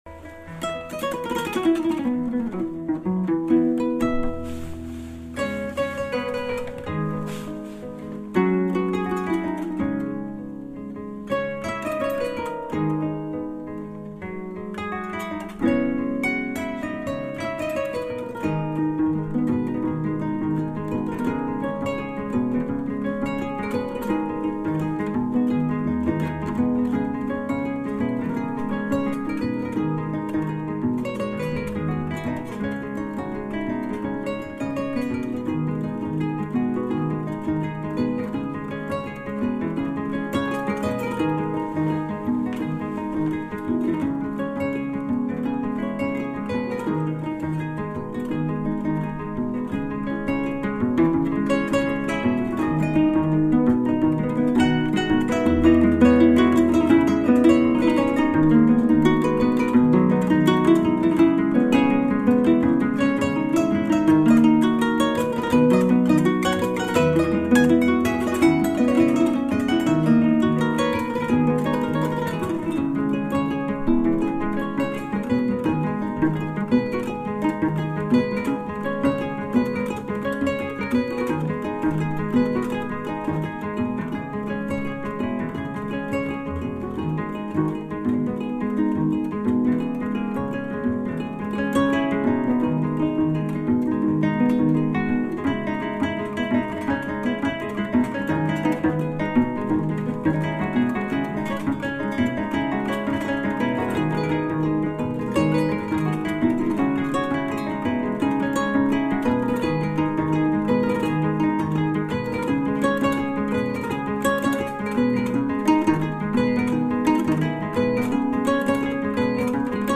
gespeeld op de kora